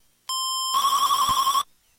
描述：带32mb卡和i kimu软件的gameboy样品